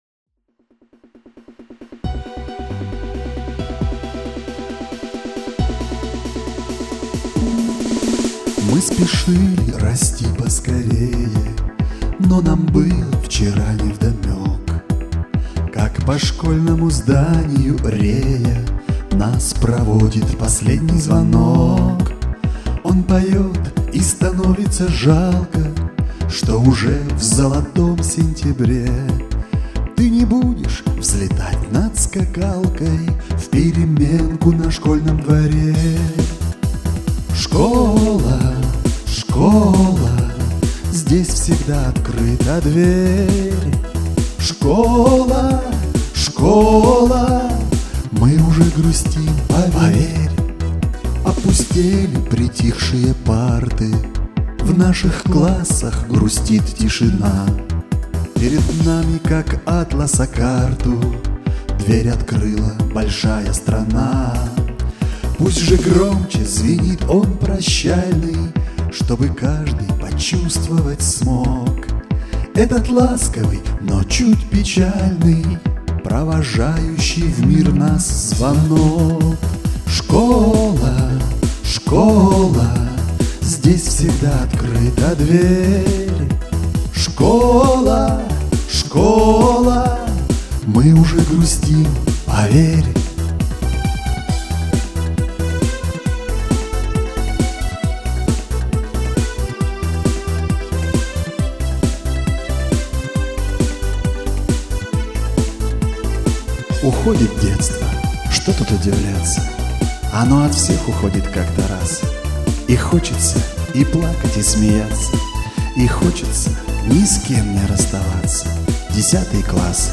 Мінусовка